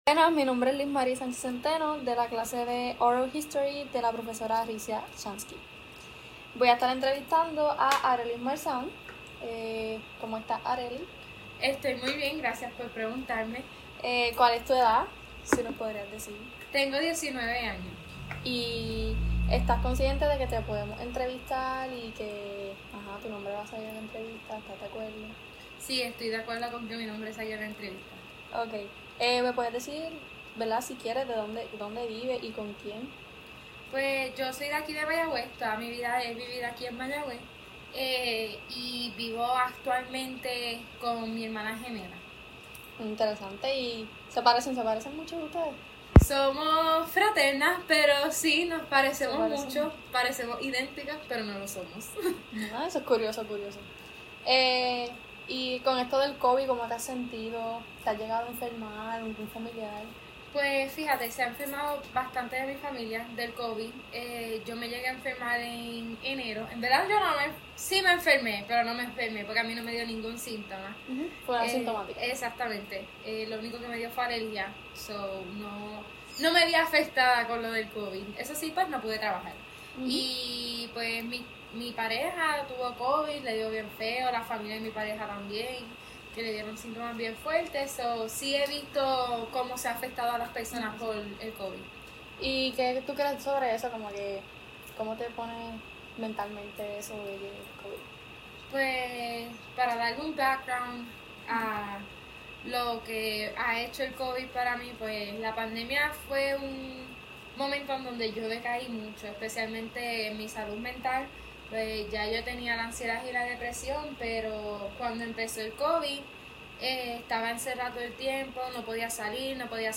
This interview addresses the injustice of gender-based prejudices. CAUTIONARY NOTE: The interview includes references to suicide which may be disturbing to some people.